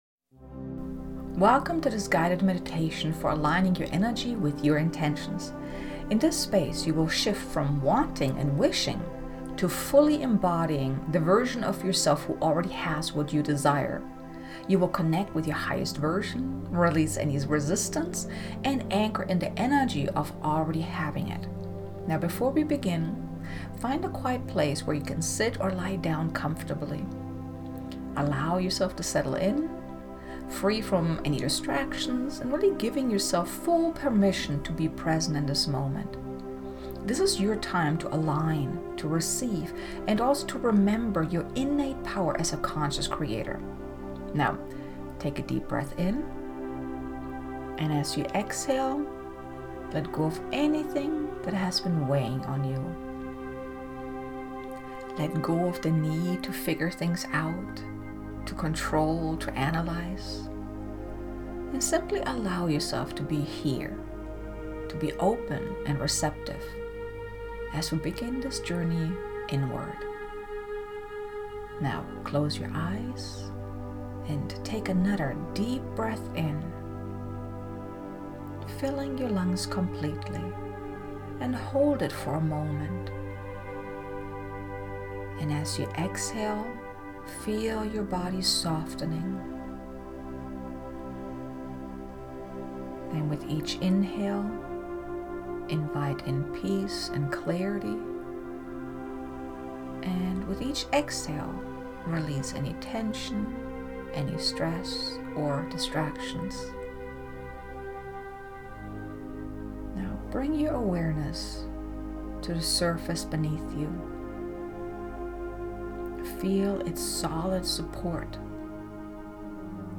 Guided Meditation: Aligning Your Energy with Your Intentions
Guided-Meditation-Aligning-Your-Energy-with-Your-Intentions.mp3